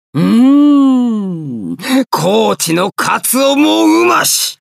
ボイスセレクションと川神通信Ｑ＆Ａです